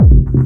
• Pushing Steely Techno Kick.wav
Pushing_Steely_Techno_Kick_mCo.wav